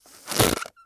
StagedTireBlowouts AZ061002
Staged Tire Blowouts with Whoopee Cushion, Cu, Long.